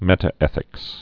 (mĕtə-ĕthĭks)